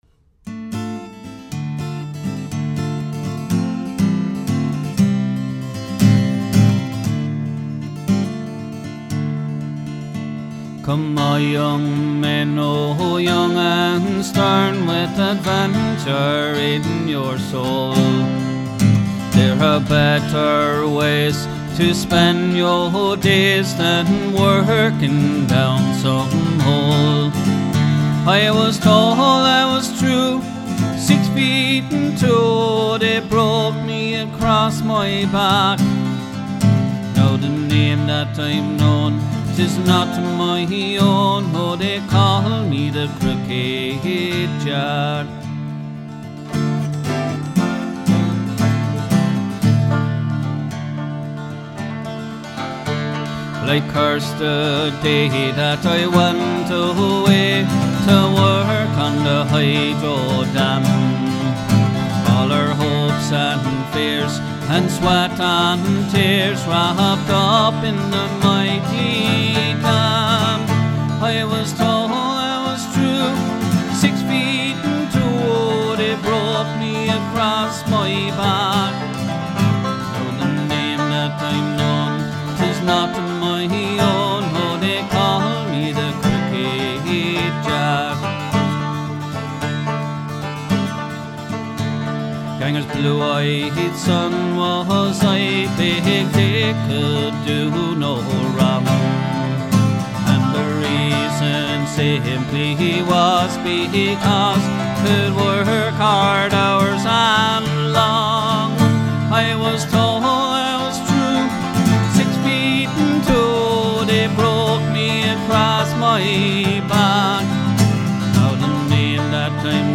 completely with the help of his voice, guitar and a Bodhran.
• Crocked Jack (Irish Folk)